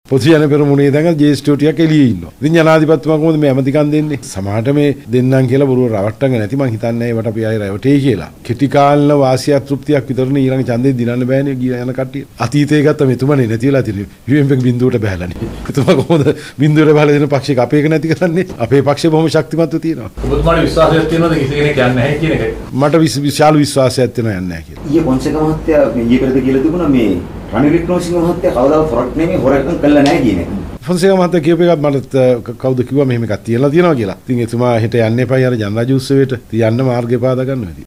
ඒ මහතා මේ බව කියා සිටියේ අද(17) පැවැති මාධ්‍ය හමුවකට එක්වෙමිනි.
RANJITH-MADDUMABANDARA.mp3